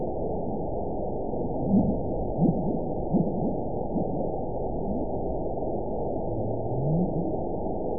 event 917397 date 03/31/23 time 10:43:38 GMT (2 years, 1 month ago) score 9.39 location TSS-AB04 detected by nrw target species NRW annotations +NRW Spectrogram: Frequency (kHz) vs. Time (s) audio not available .wav